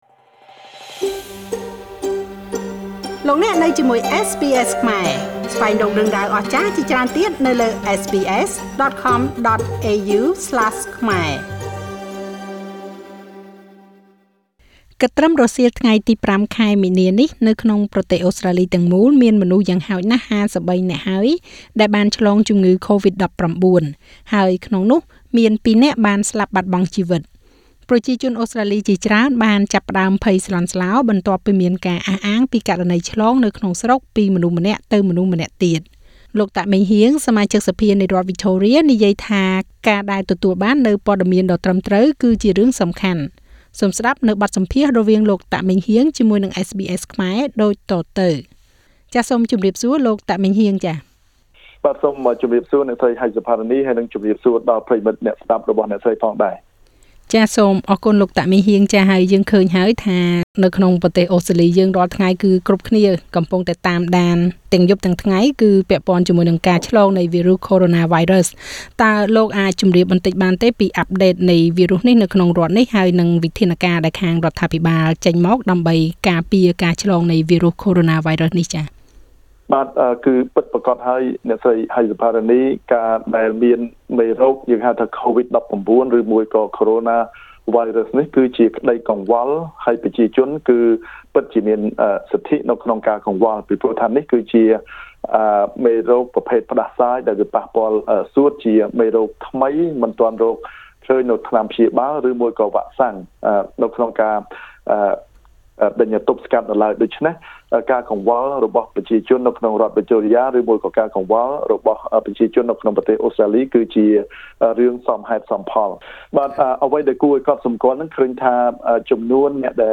Victorian MP Meng Heang Tak at SBS studio in Melbourne Source: SBS Khmer